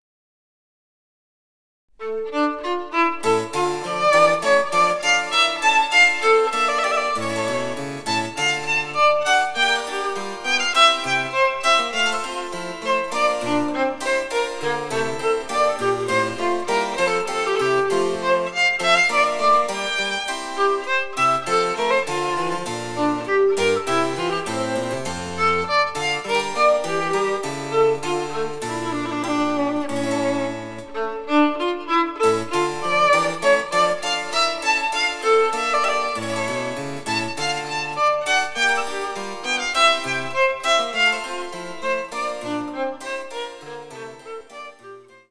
■ヴァイオリンによる演奏（ニ短調）